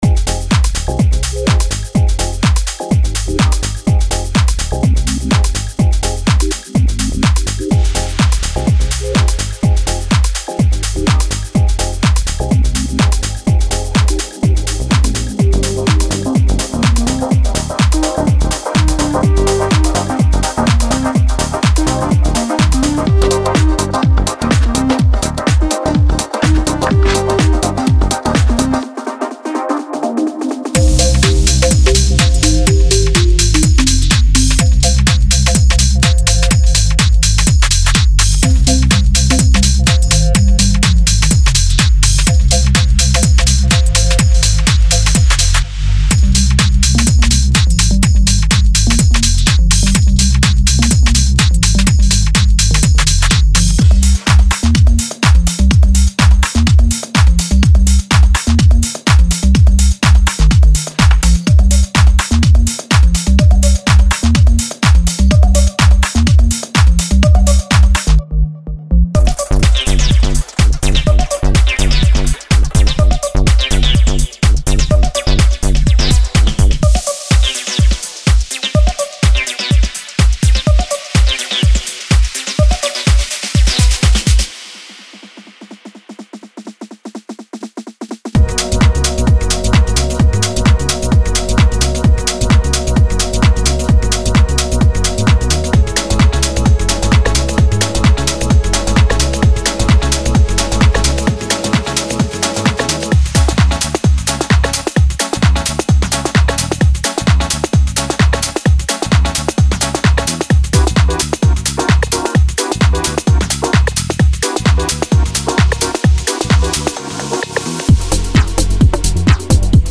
デモサウンドはコチラ↓
Genre:Deep House